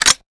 weapon_foley_drop_18.wav